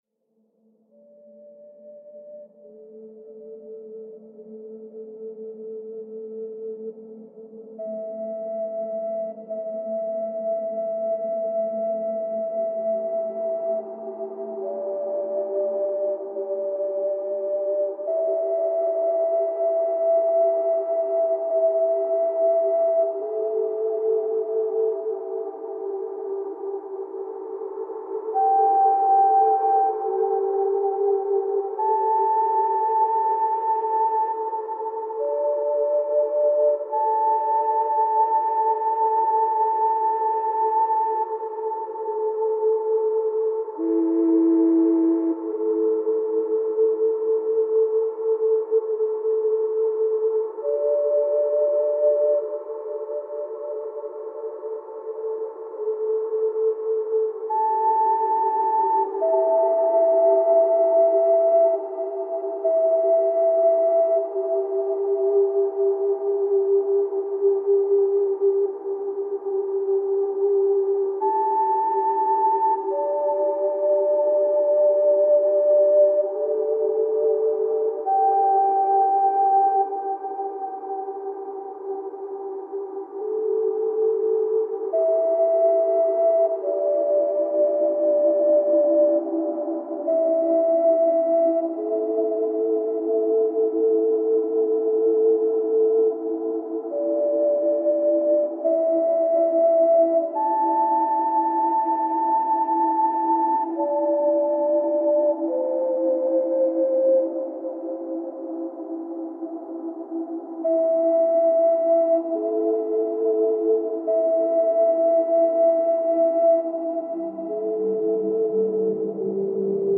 Мелодии для расслабления тела